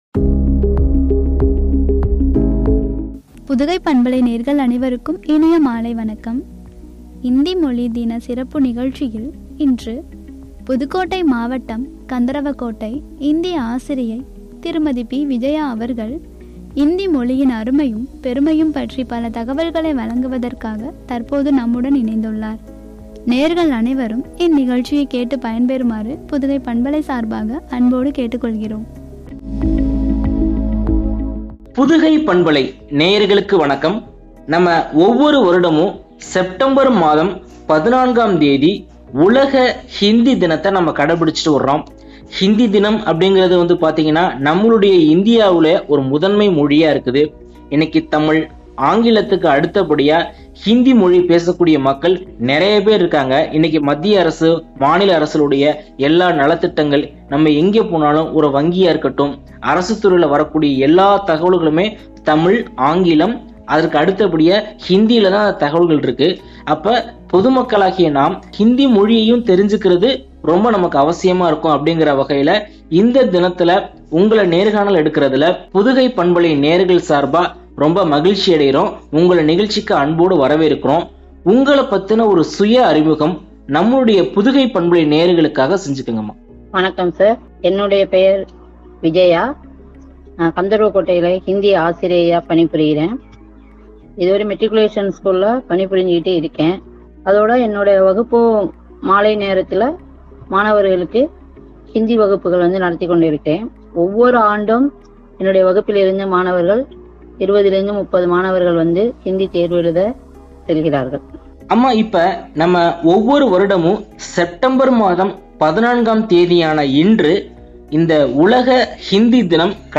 பெருமையும்” எனும் தலைப்பில் வழங்கிய உரையாடல்.